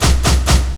02_27_drumbreak.wav